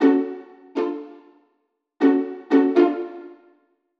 Pensive (Strings) 120BPM.wav